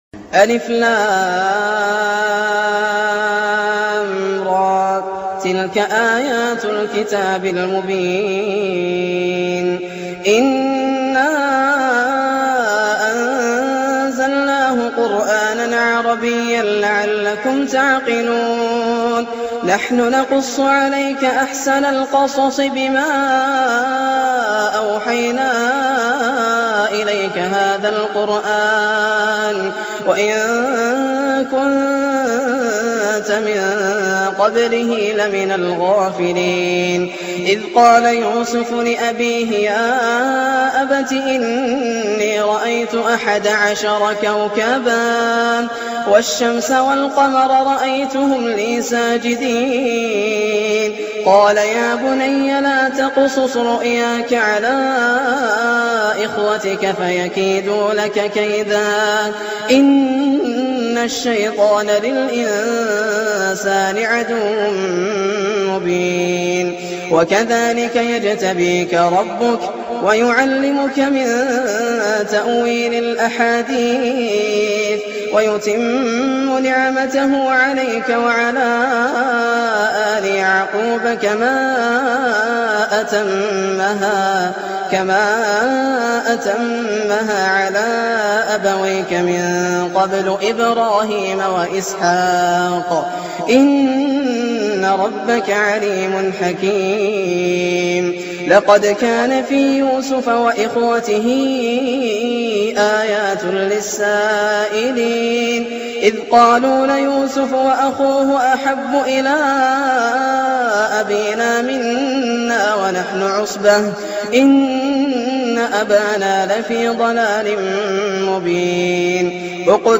سورة يوسف أول اصدار سجله الشيخ د. ياسر الدوسري من عام 1419هـ > الإصدارات > المزيد - تلاوات ياسر الدوسري